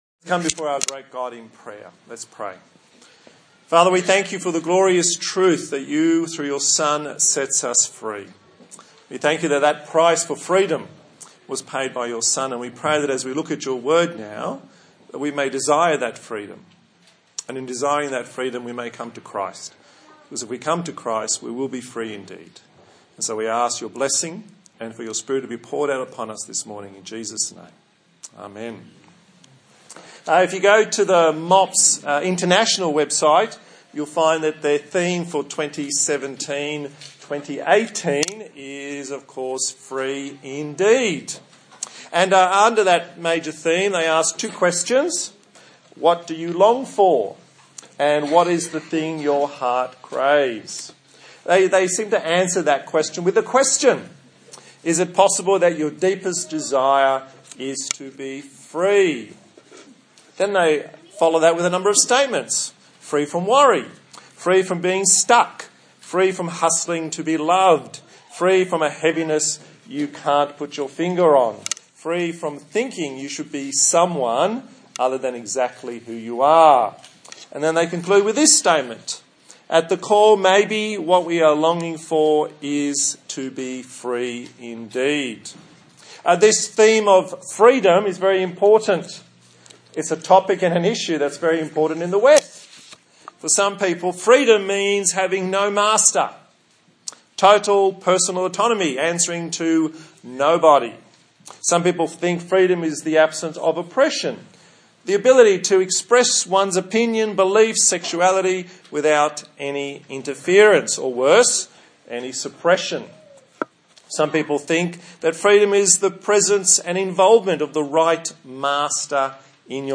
John Passage: John 8:31-36 Service Type: Sunday Morning